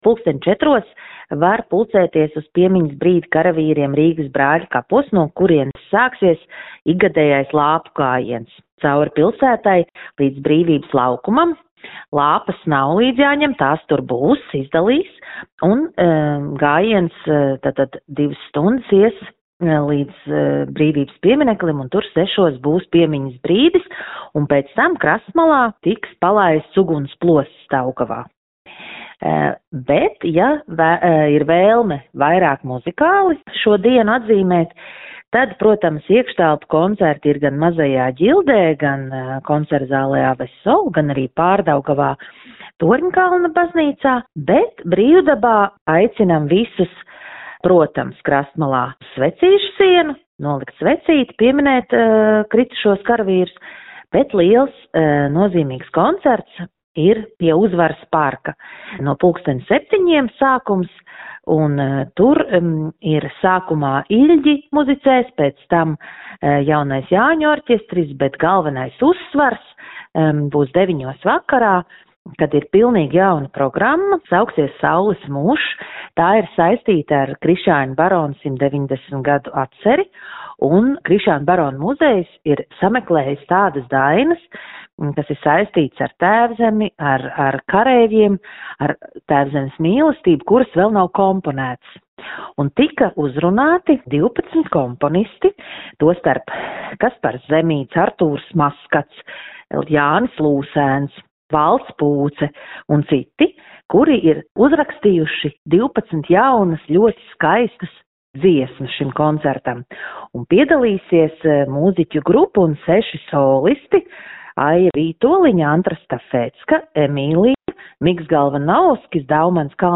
Riga_11novembris_pasakumi_II.mp3